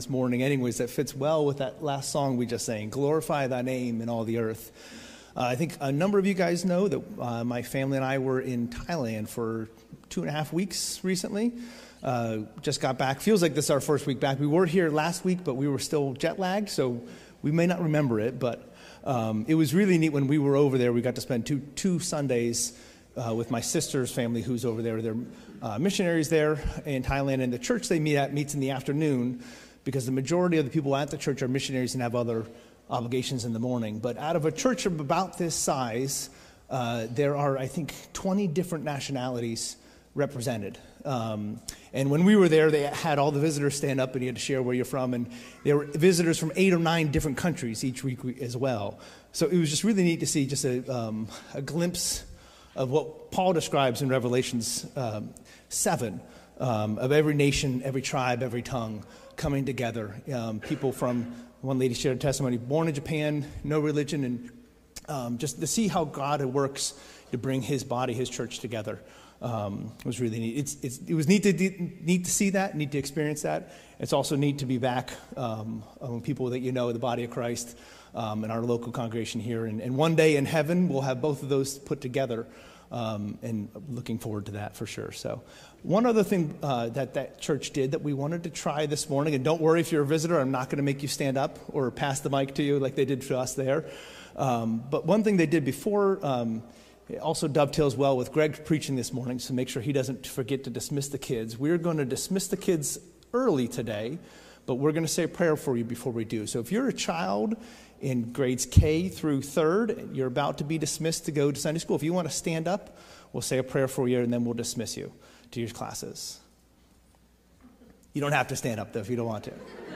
Sermons | Calvary Baptist Church